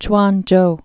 (chwänjō)